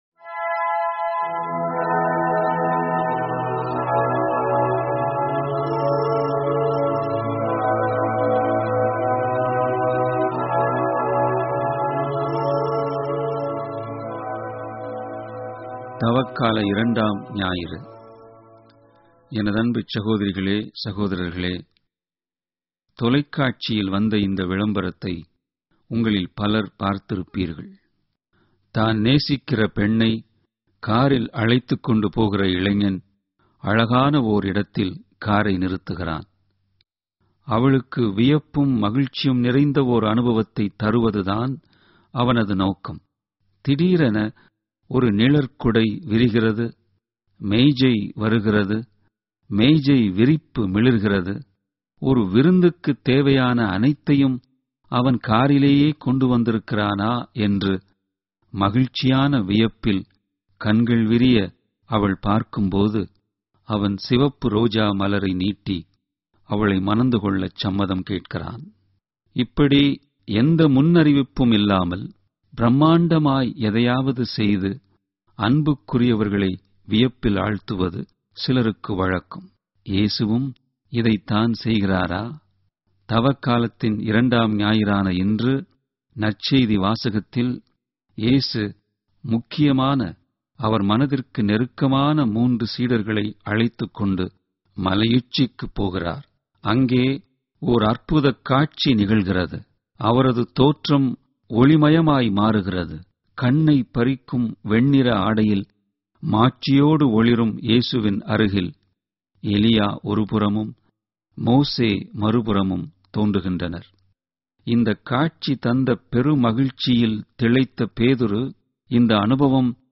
Homilies